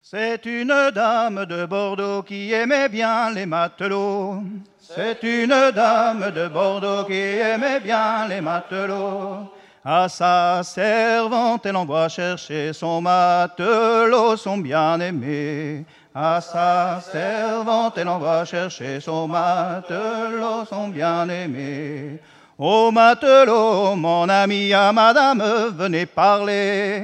Festival de la chanson traditionnelle - chanteurs des cantons de Vendée
Pièce musicale inédite